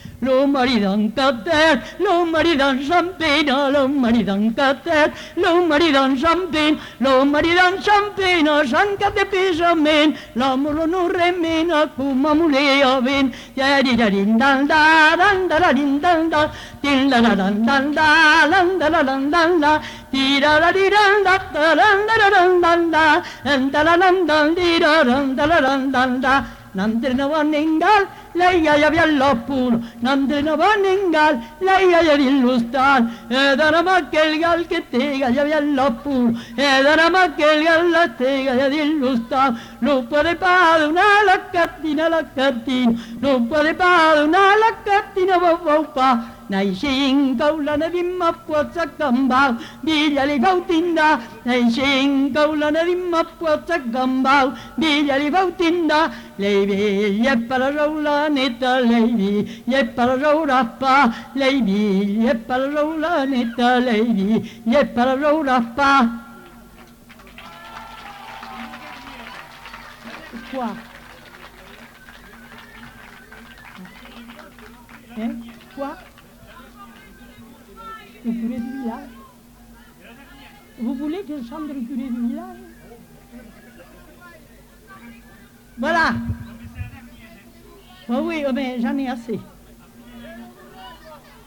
Lieu : Saint-Amans-des-Cots
Genre : chant
Type de voix : voix de femme
Production du son : chanté
Danse : bourrée